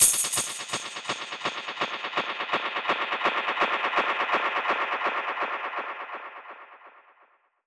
Index of /musicradar/dub-percussion-samples/125bpm
DPFX_PercHit_D_125-04.wav